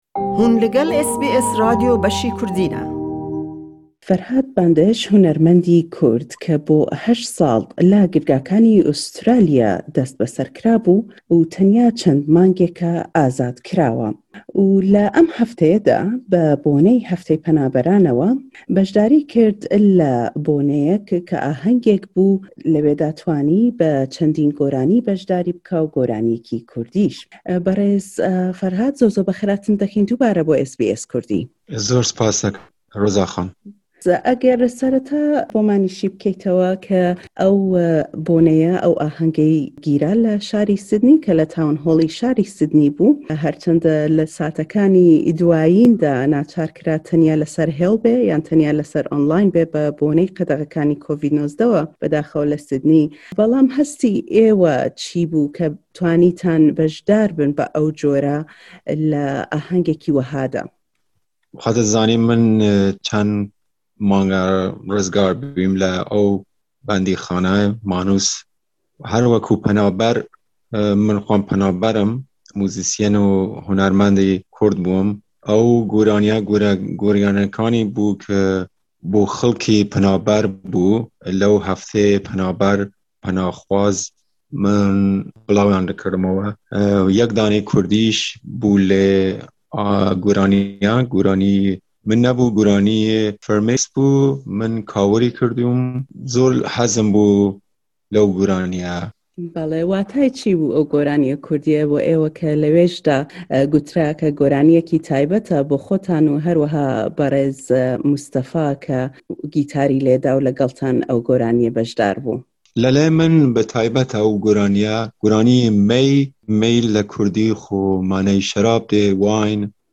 SBS Kurdish